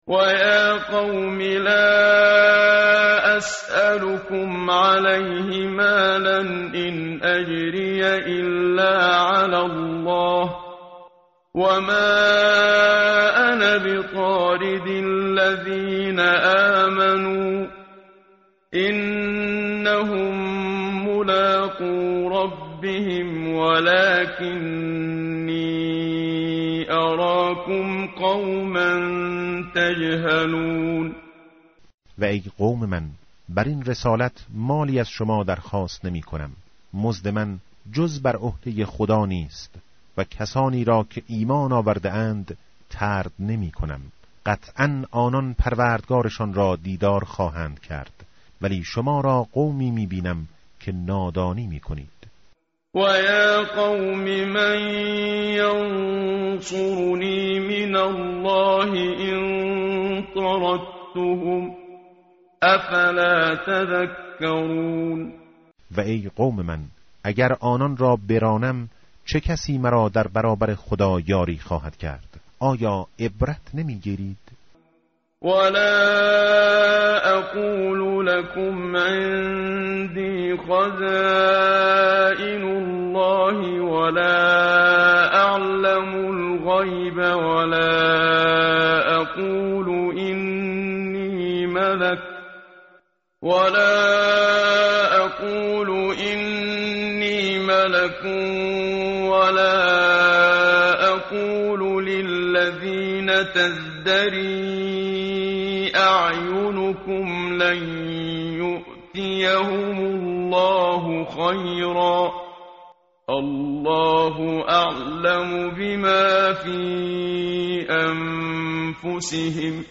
متن قرآن همراه باتلاوت قرآن و ترجمه
tartil_menshavi va tarjome_Page_225.mp3